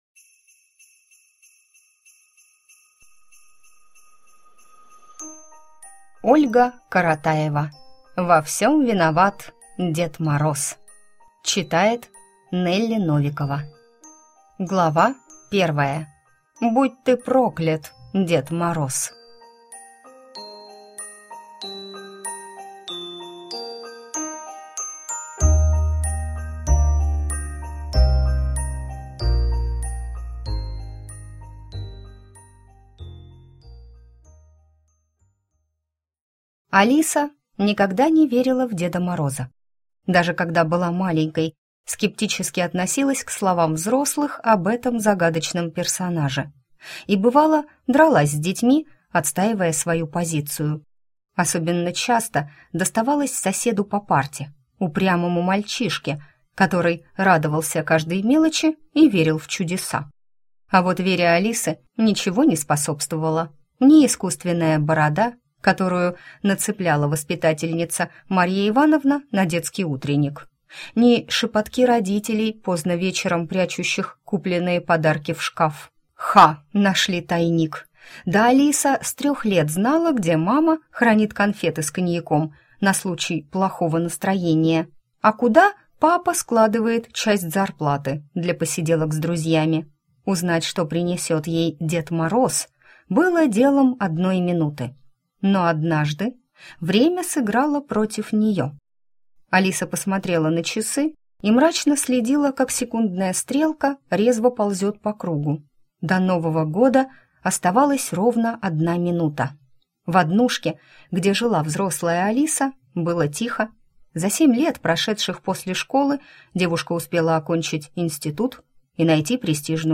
Аудиокнига Во всём виноват Дед Мороз!